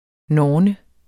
norne substantiv, fælleskøn Bøjning -n, -r, -rne Udtale [ ˈnɒːnə ] Oprindelse fra norrønt norn 'skæbnegudinde', af uvis oprindelse Betydninger 1.